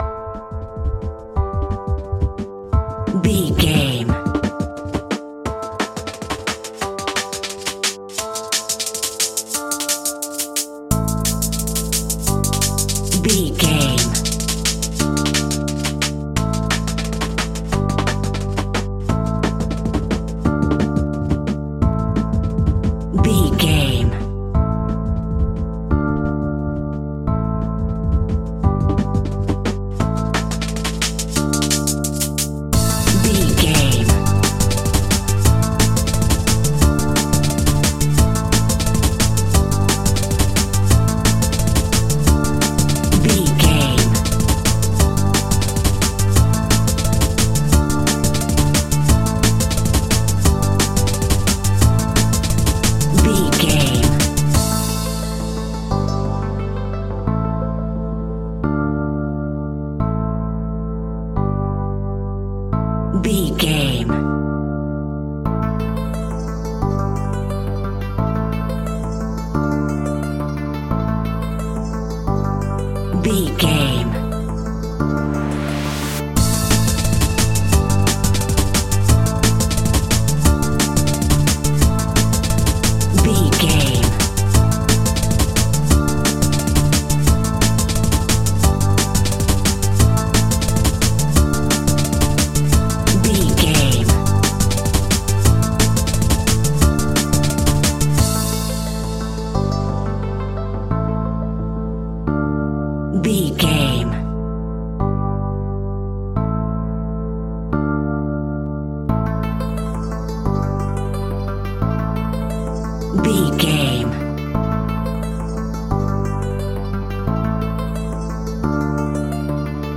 Aeolian/Minor
Fast
aggressive
dark
driving
energetic
groovy
drum machine
synthesiser
electric piano
bass guitar
sub bass
synth leads